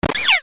sprigatito_ambient.ogg